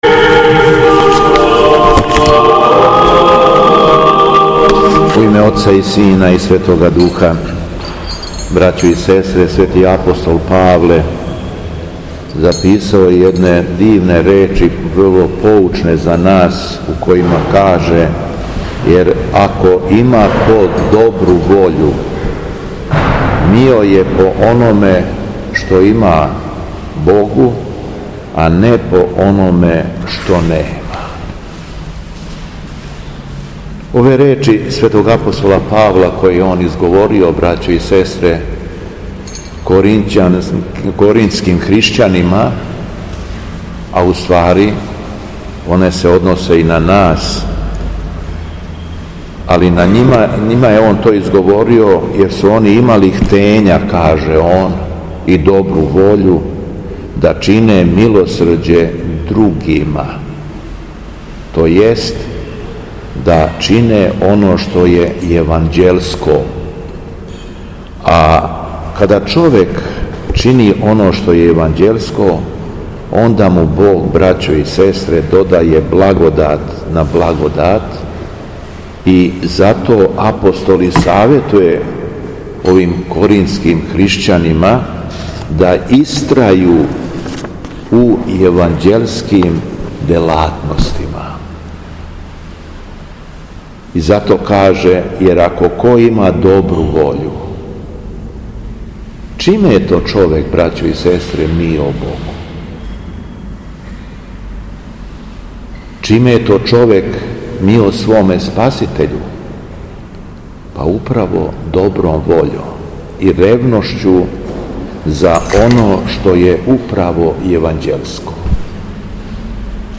Беседа Његовог Високопреосвештенства Митрополита шумадијског г. Јована
У понедељак сирни, када наша света Црква прославља светог мученика Ђорђа Кратовца, Његово Високопреосвештенство митрополит шумадијски Господин Јован служио је свету архијерејску литургију у храму Светога Саве у крагујевачком насељу Аеродром.